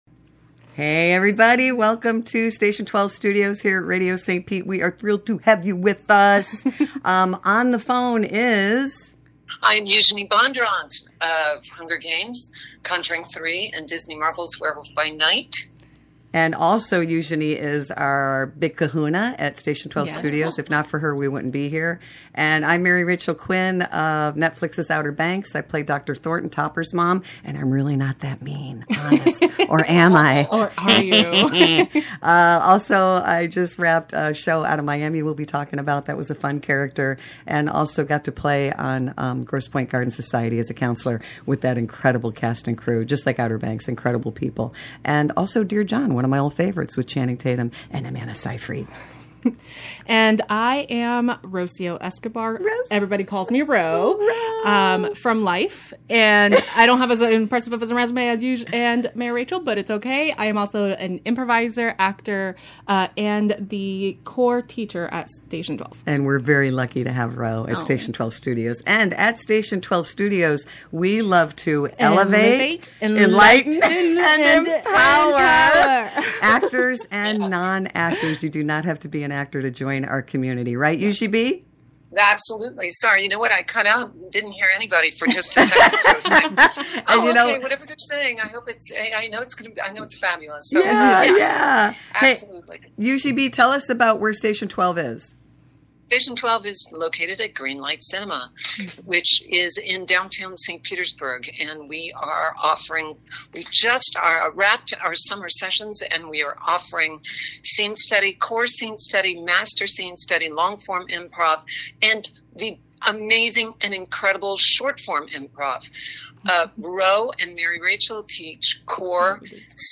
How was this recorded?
The girls are back on air...and tik tok!